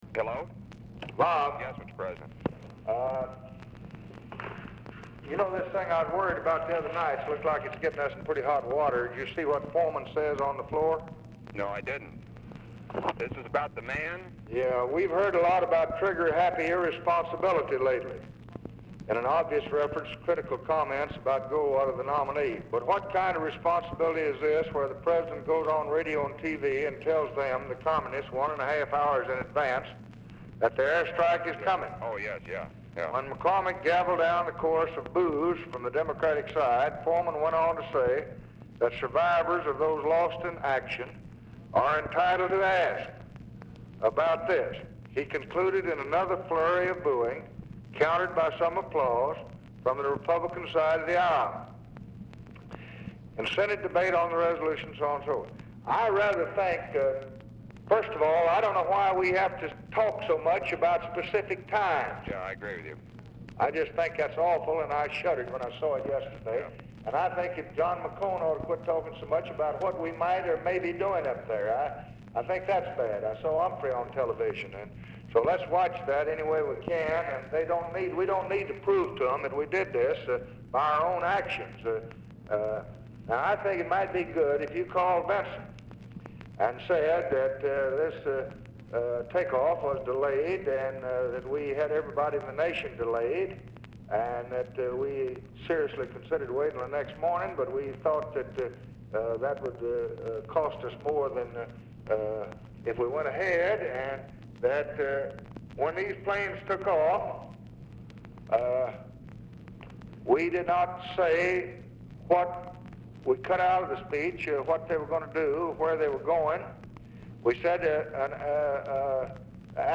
Telephone conversation # 4799, sound recording, LBJ and ROBERT MCNAMARA, 8/7/1964, 1:40PM | Discover LBJ
Format Dictation belt
Location Of Speaker 1 Oval Office or unknown location